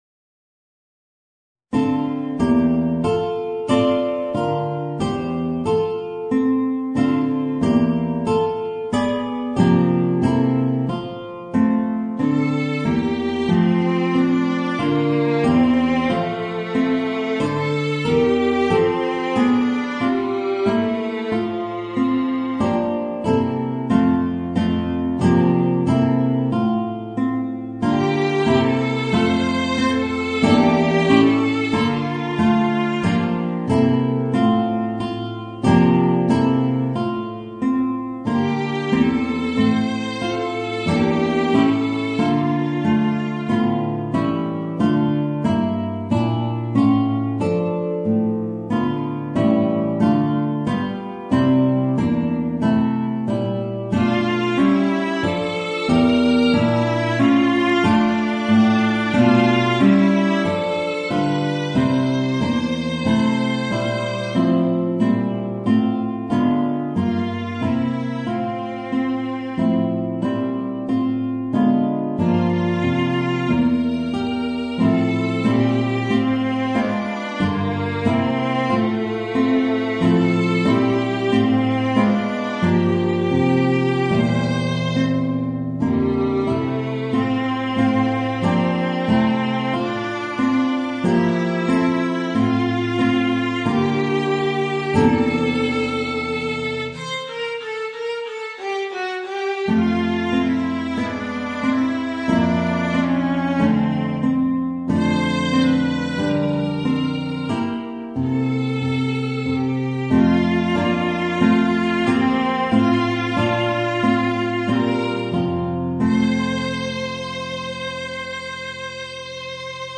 Voicing: Guitar and Viola